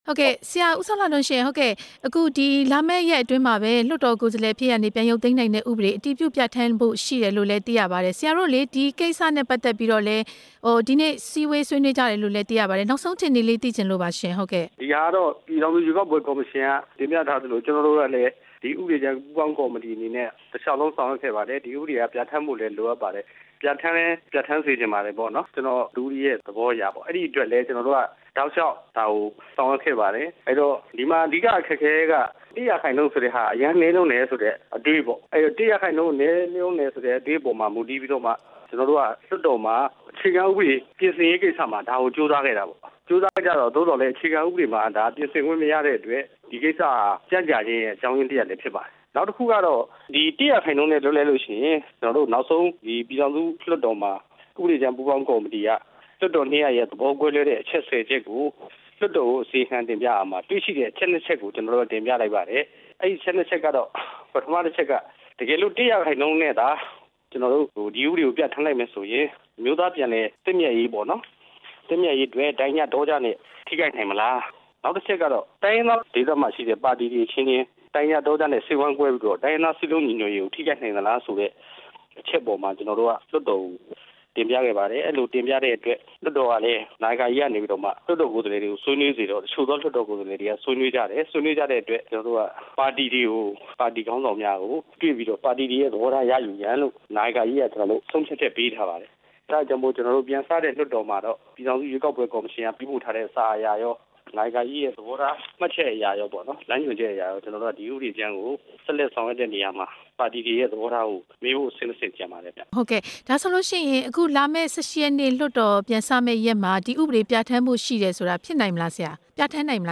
မေးမြန်းထားပါတယ်။